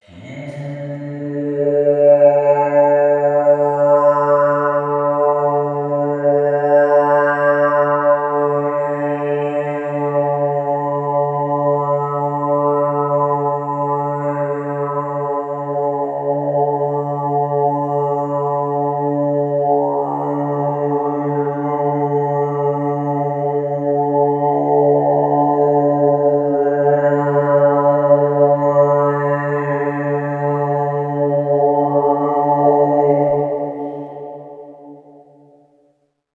Impulse Response for Busseto Cathedral
III. Bass Convolved with Self (as used in Temple)
Convolved with Busseto